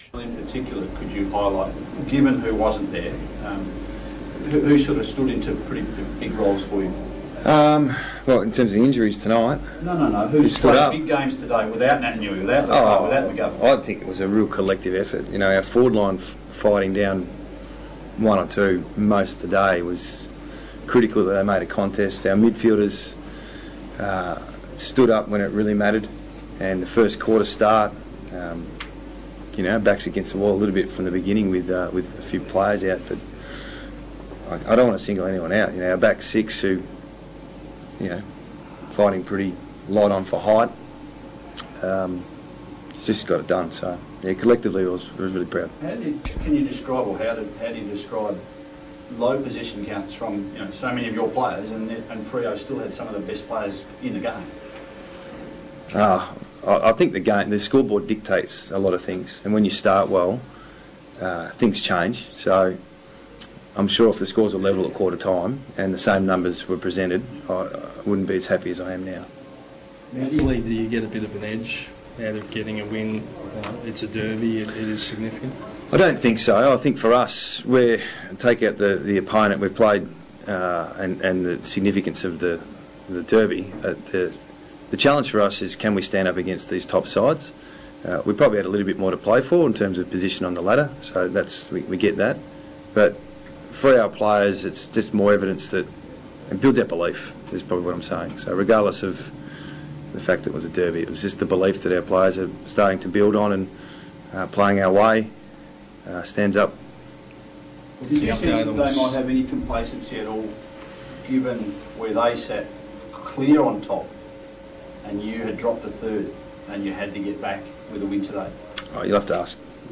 Adam Simpson Post Game Press Conference
West Coast Eagles coach Adam Simpson speaks to the media after their 24-point win over the Fremantle Dockers